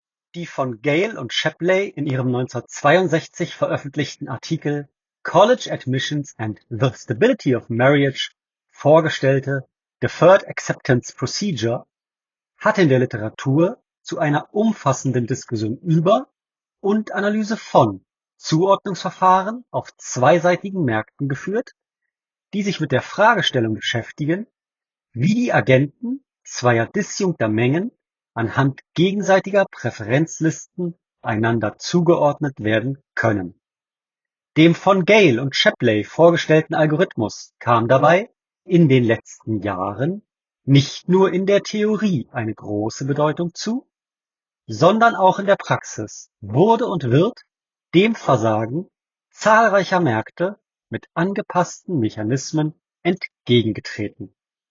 Huawei FreeBuds Pro 4 – Mikrofonqualität bei sehr lauter Umgebung